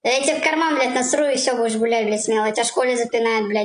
ia tebe v karman nasru Meme Sound Effect